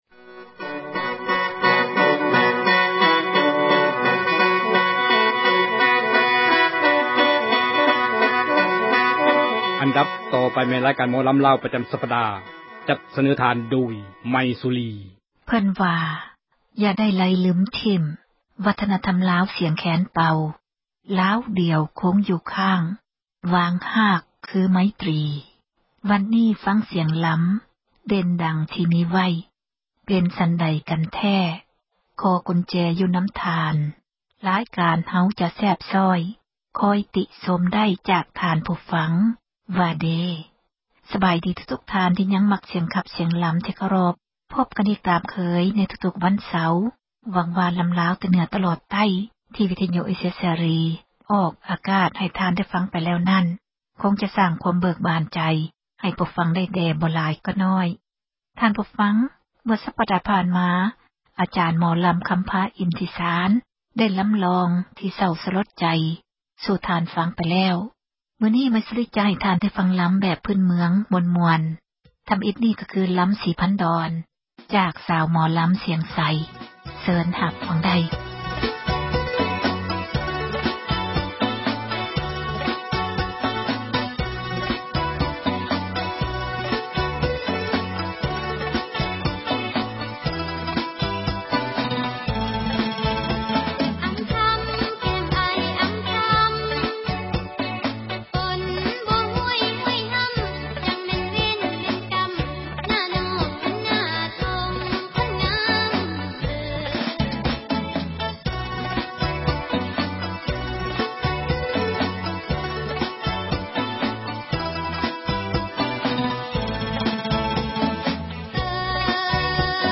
ຣາຍການ ໝໍລຳລາວ ປະຈຳ ສັປດາ.